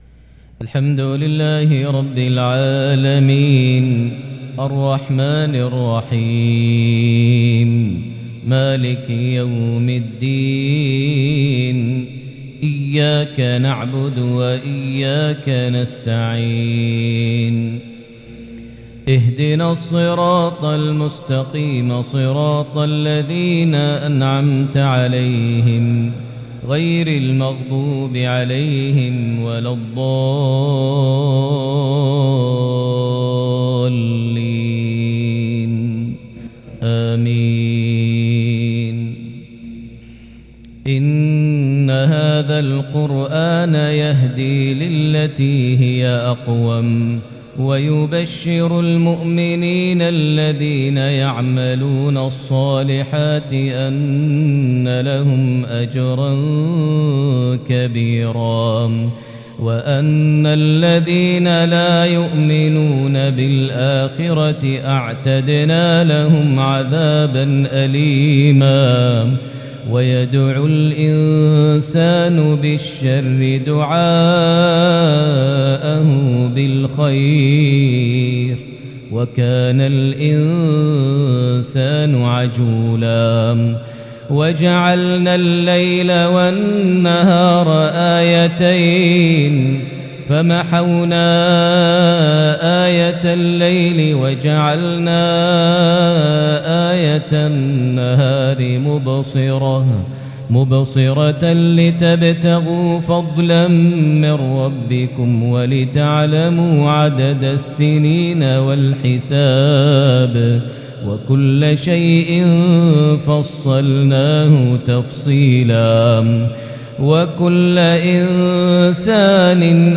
maghrib 2-3-2022 prayer from Surah Al-Isra 9-17 > 1443 H > Prayers - Maher Almuaiqly Recitations